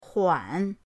huan3.mp3